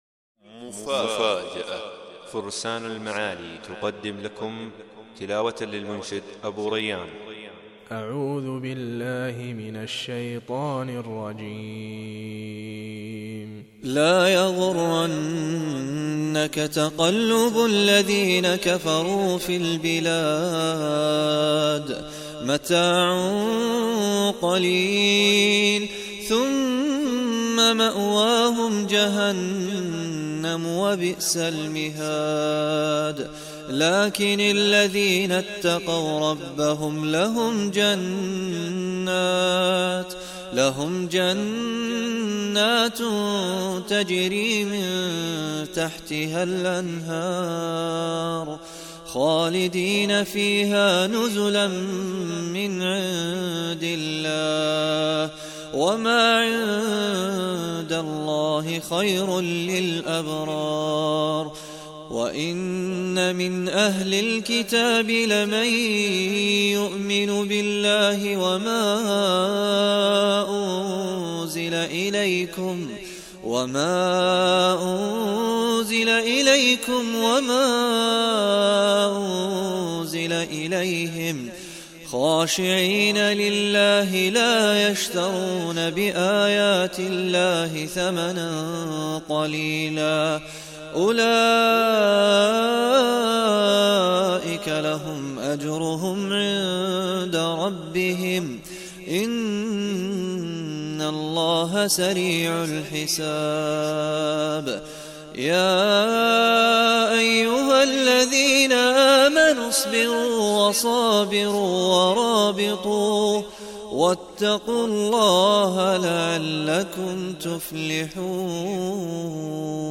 مقطع قرآن بصوت المنشد المبدع
ماشاء الله صوت جداً راااائع ..